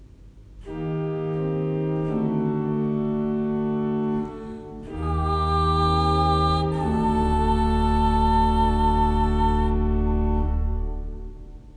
Solo (David Mass recordings)
solo-amen.m4a